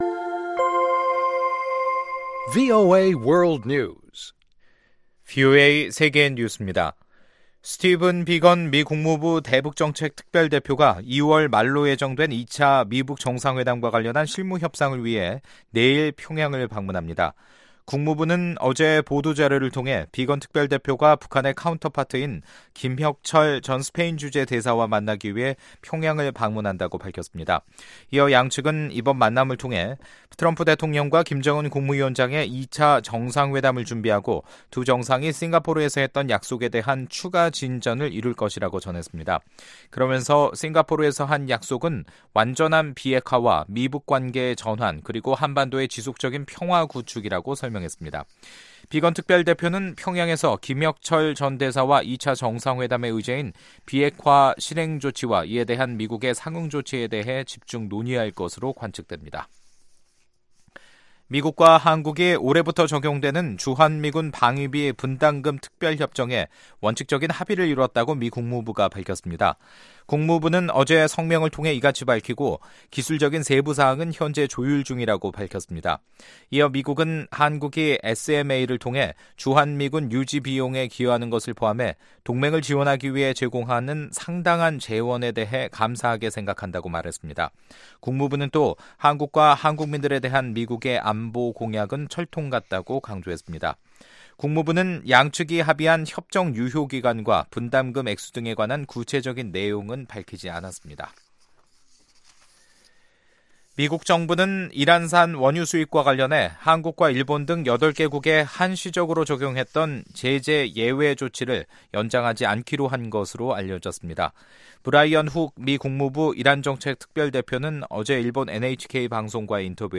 VOA 한국어 간판 뉴스 프로그램 '뉴스 투데이', 2019년 2월 5일 2부 방송입니다. 스티븐 비건 미 국무부 대북정책특별대표가 2차 미북 정상회담 준비와 실무협상을 위해 6일 평양을 방문합니다. 미국인 절반 이상이 2차 미-북 정상회담 개최에 부정적 시각을 갖고 있다는 설문조사 결과가 나왔습니다.